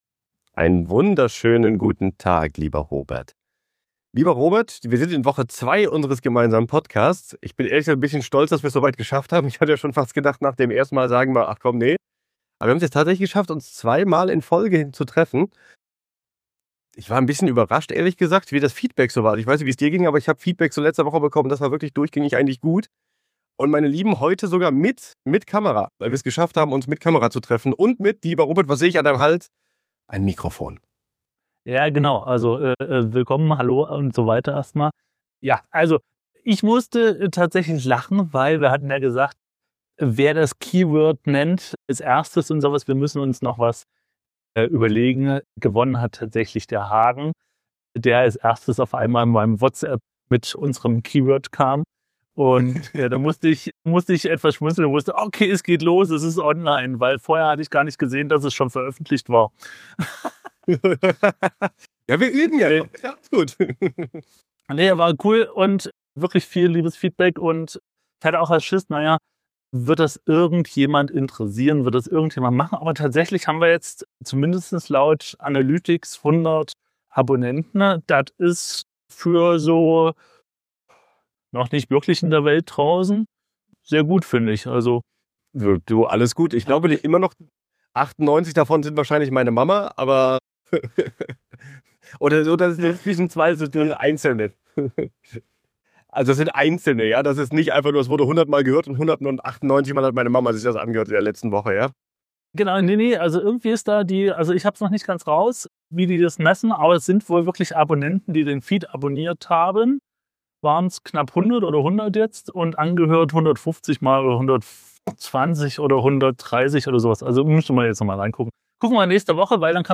Ton? Gibts! (mit etwas stocken) Zwischen Kalender-Burnout und tollen Kundenterminen